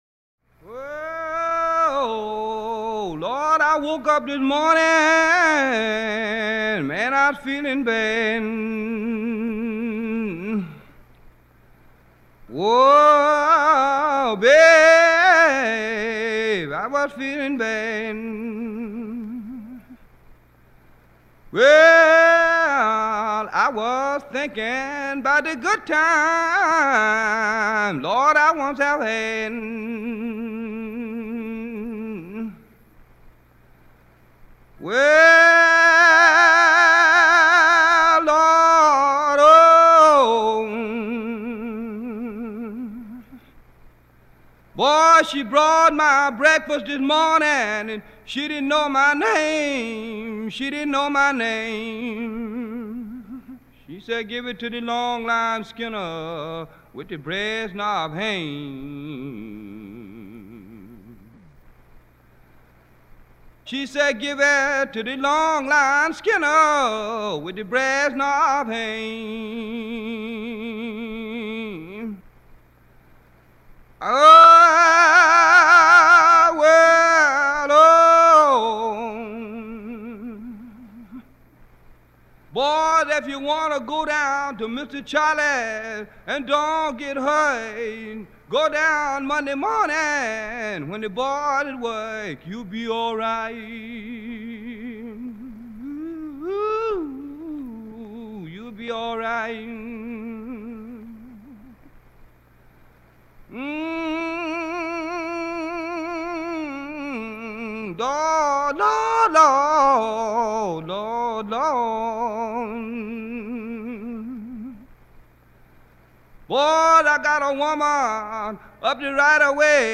Field hollers
leveecampholler.mp3